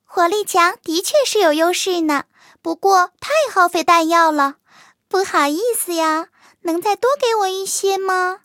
卡尔臼炮MVP语音.OGG